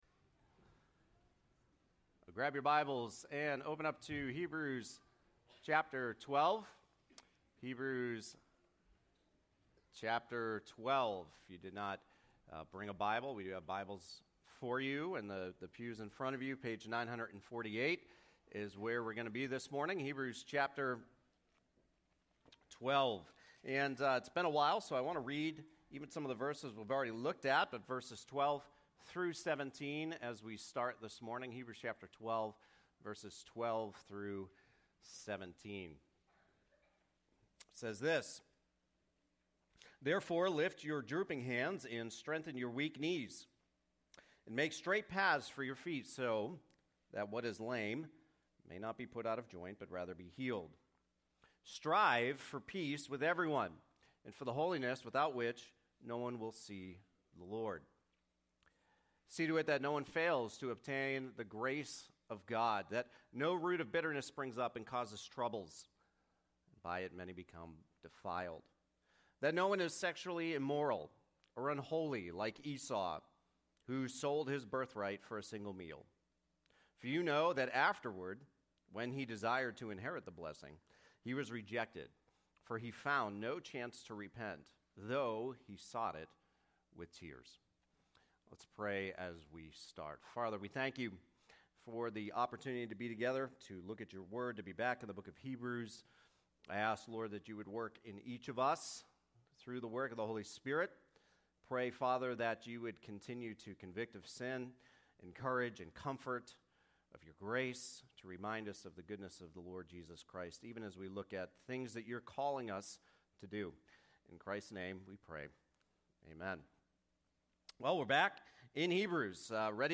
Hebrews: Jesus Is Better - Directives As You Endure - Part 2 Hebrews 12:12-17 Sermon 43